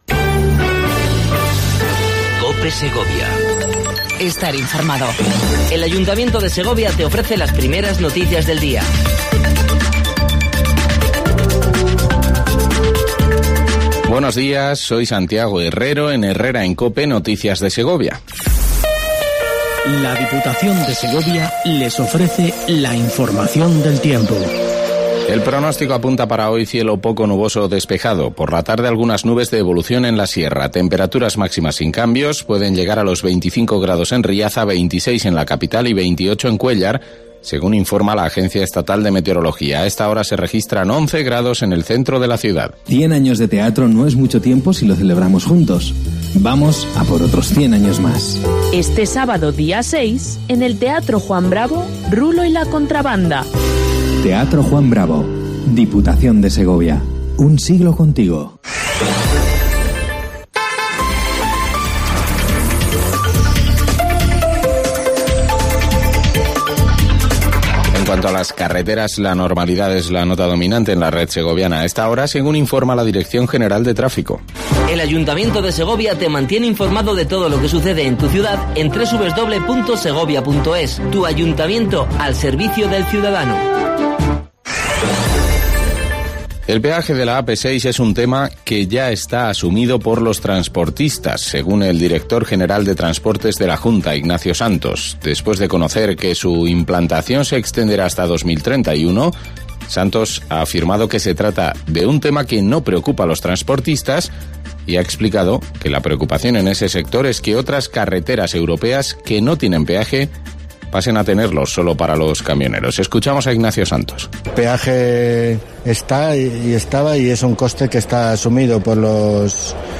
AUDIO: Primer informativo local cope segovia